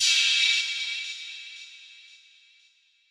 crash 1.wav